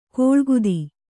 ♪ koḷguḍi